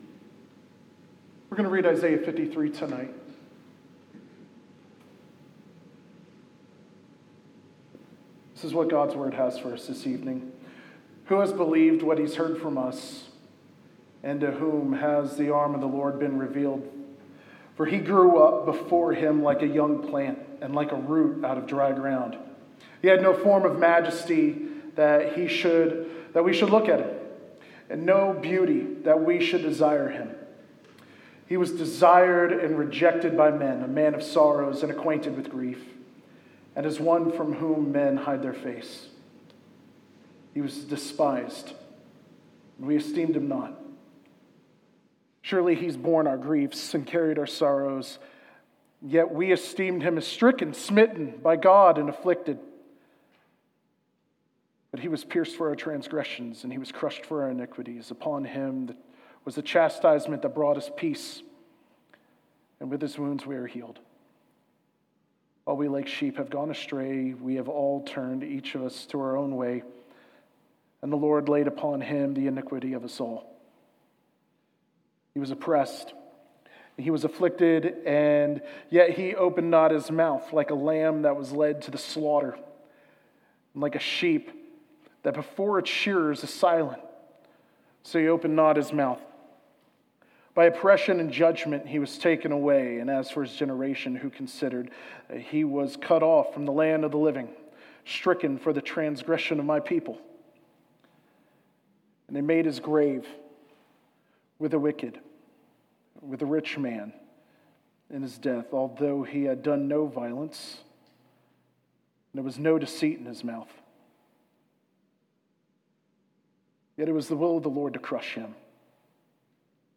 Sermons | Hazelwood Baptist Church